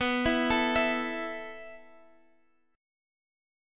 A5/B chord